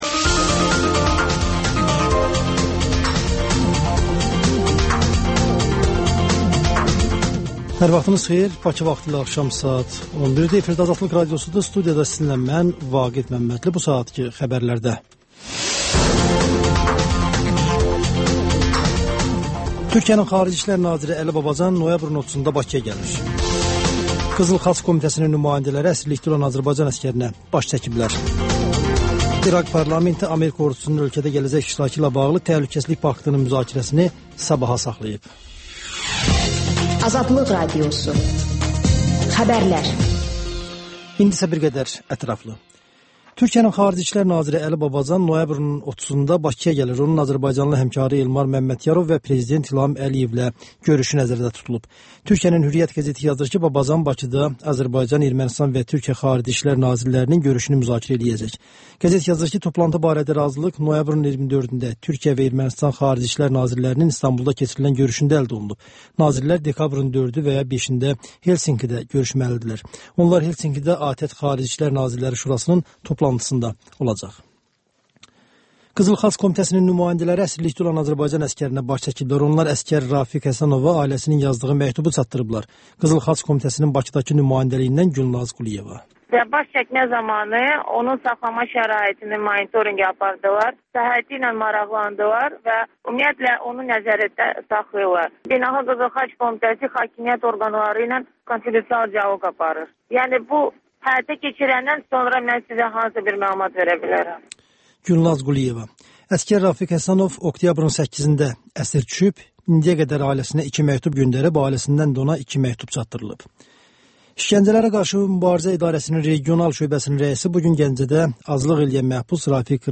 Xəbərlər, müsahibələr, hadisələrin müzakirəsi, təhlillər, sonda 14-24: Gənclər üçün xüsusi veriliş